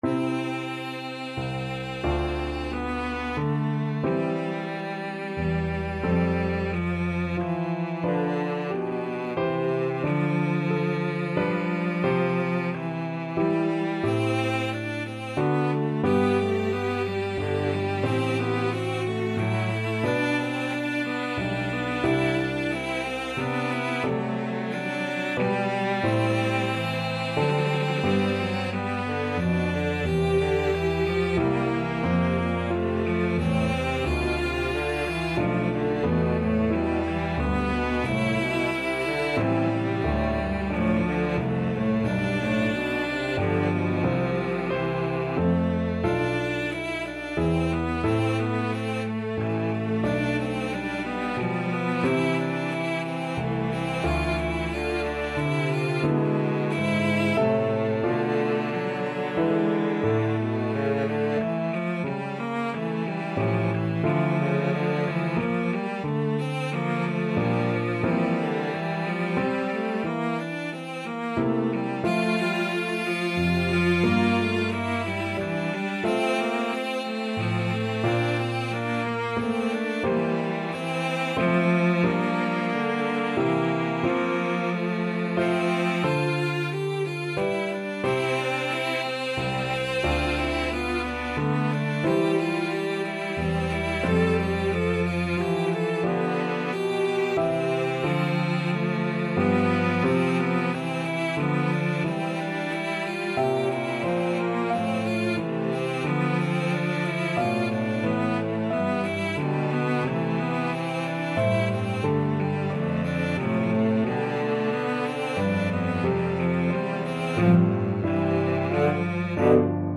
Cello 1Cello 2
3/4 (View more 3/4 Music)
Largo ma non tanto ( = c. 90)
Classical (View more Classical Cello Duet Music)